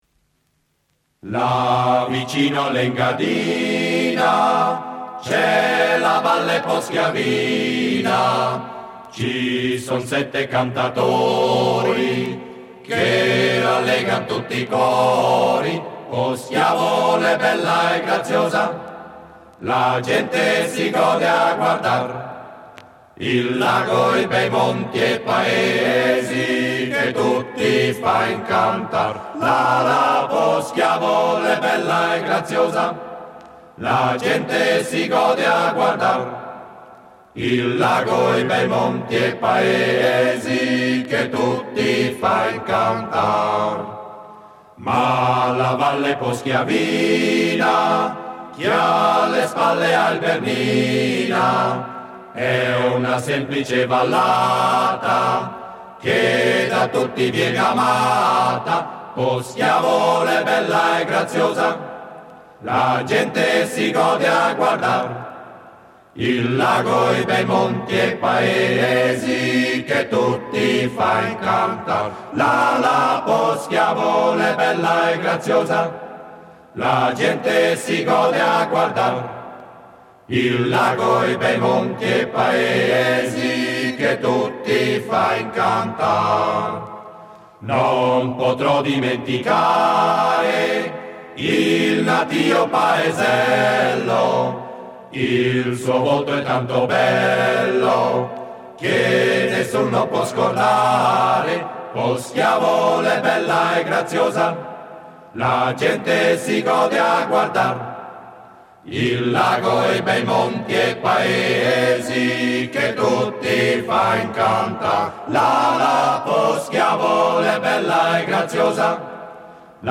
Un assaggio della musica popolare delle valli ticinesi e del Grigioni italiano e delle canzoni che hanno accompagnato l'epoca migratoria.